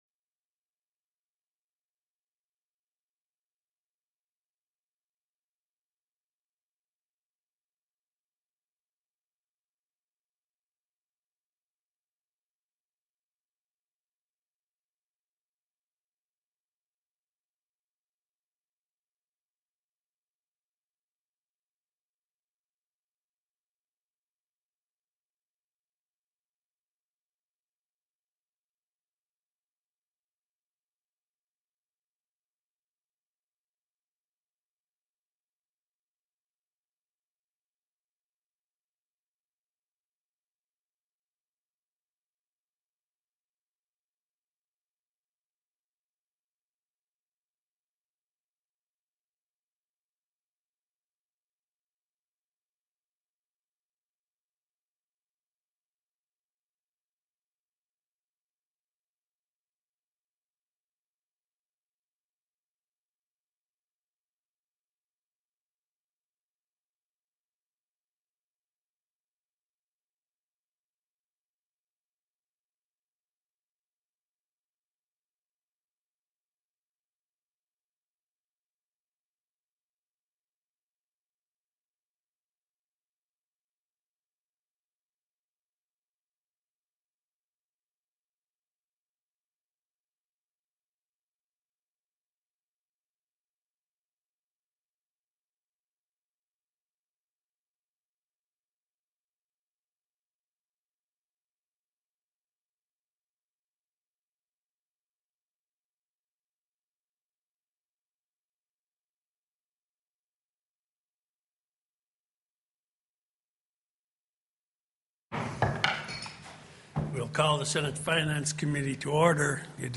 The audio recordings are captured by our records offices as the official record of the meeting and will have more accurate timestamps.
HB 78 RETIREMENT SYSTEMS; DEFINED BENEFIT OPT. TELECONFERENCED